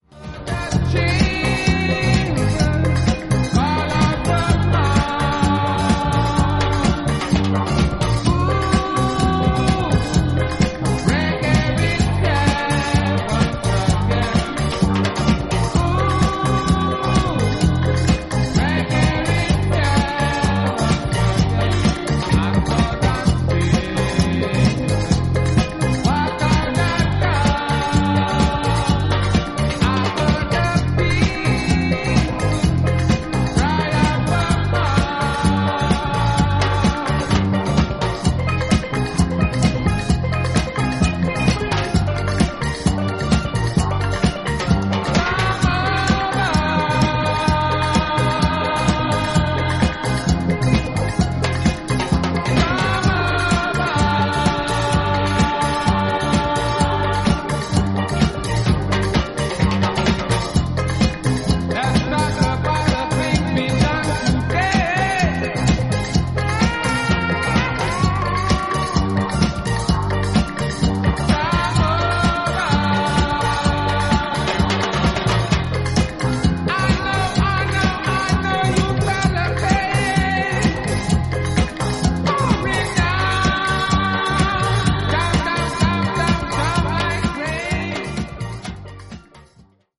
UKアフリカン/カリビアン混成バンド